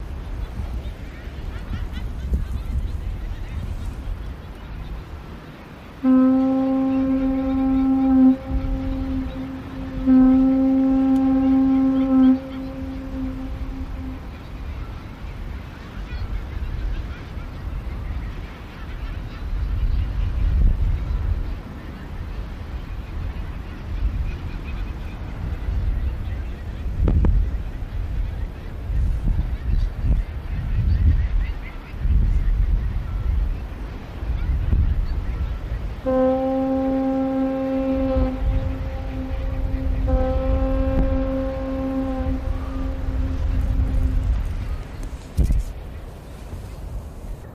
Port Background, Distant Fog Horn, Lots Of Gulls